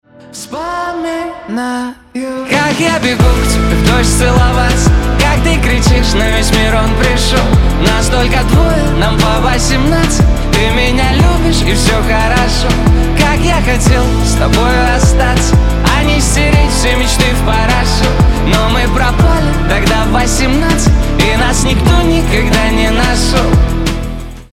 • Качество: 320, Stereo
мужской голос
спокойные